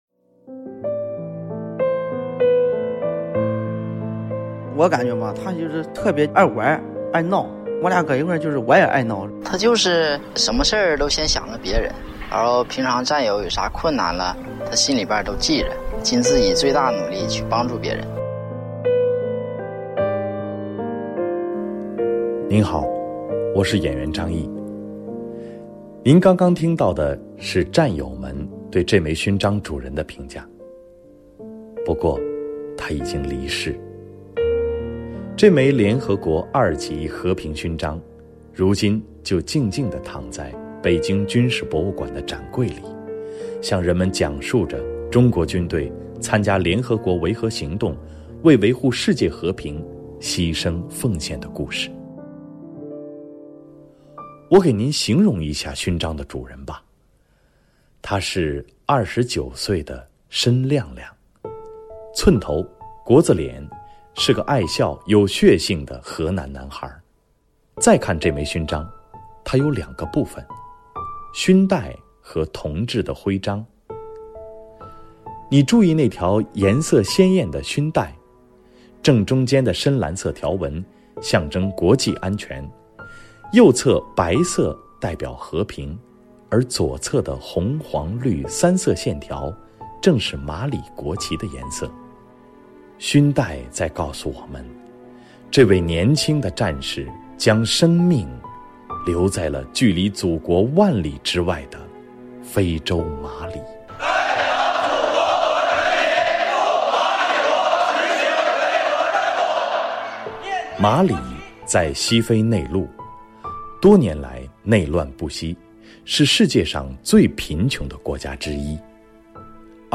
关键词:CNR,中国之声,大国军藏,收藏,解说,军事
《大国军藏》十件珍品的声音导览。